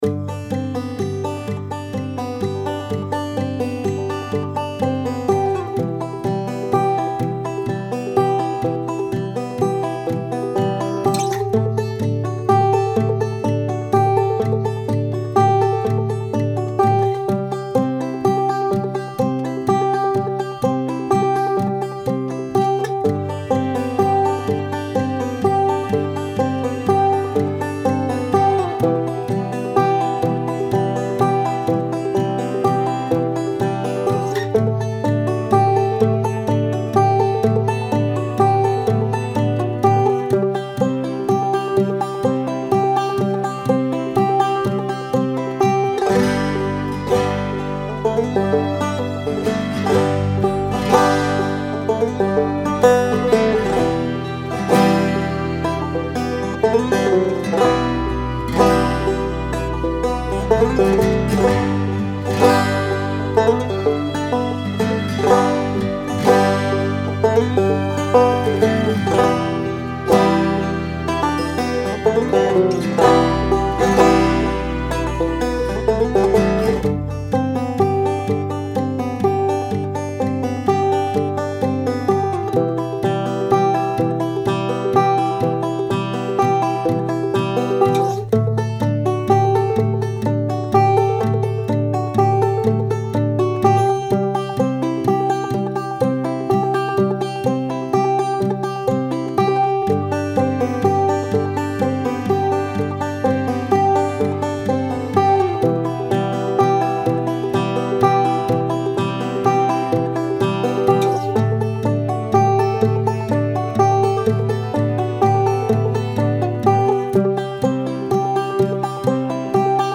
is a folk song with contemplative vibes.
130 BPM
folk contemplative acoustic guitar piano strings